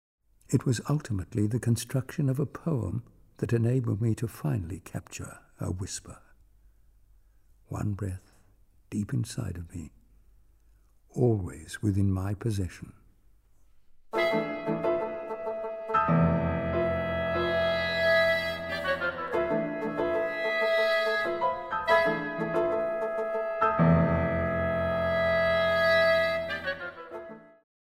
Studio 420, Ferry Road, Brisbane, 6 – 8 February 2012